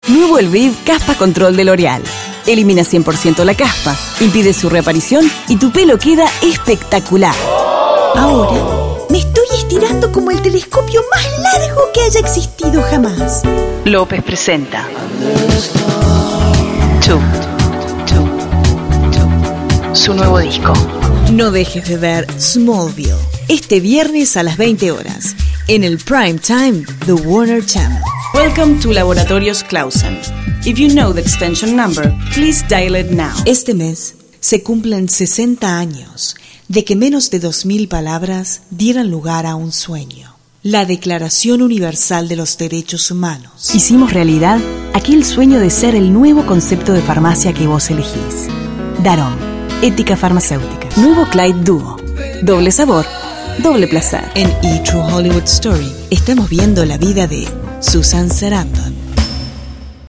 Never any Artificial Voices used, unlike other sites.
Female
Teenager (13-17), Adult (30-50)
Real, fresh and conversational for commercials, imaging and tv and radio promo, confident and caring form narrations. Professional and credible for presentations.
Main Demo
All our voice actors have professional broadcast quality recording studios.